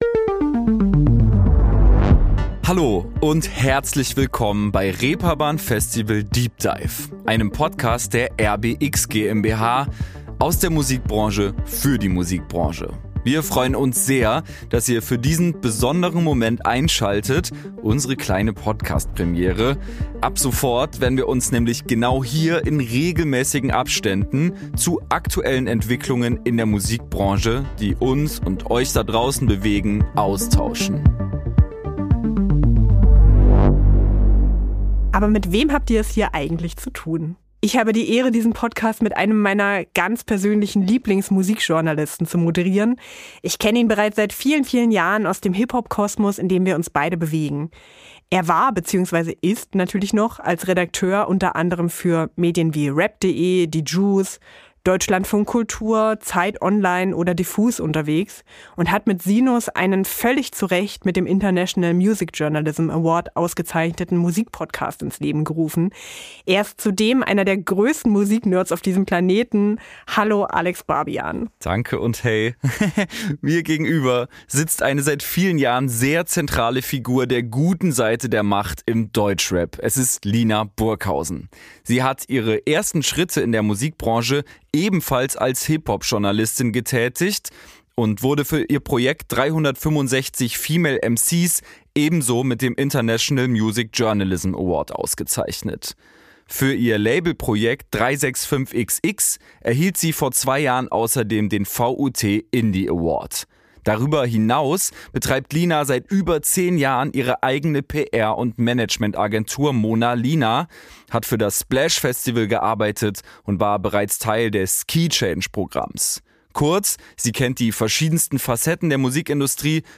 Studio: German Wahnsinn